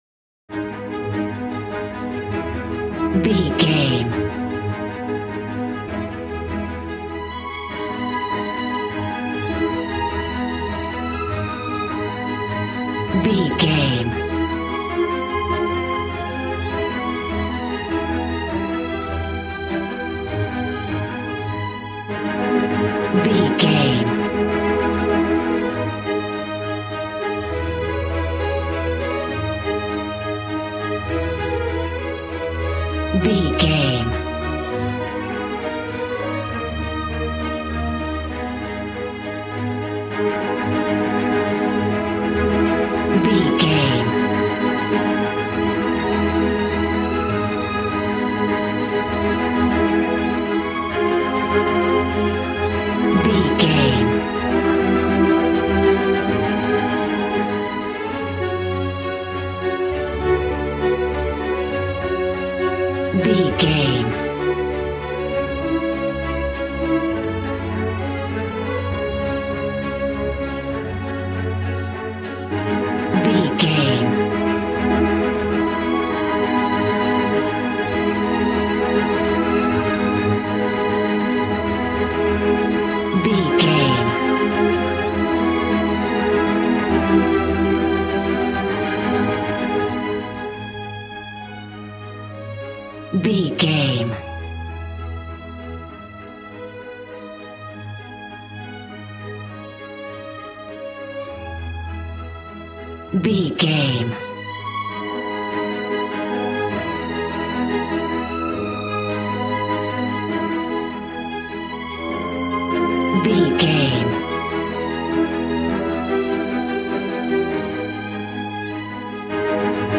A classical music mood from the orchestra.
Regal and romantic, a classy piece of classical music.
Aeolian/Minor
A♭
regal
cello
violin
strings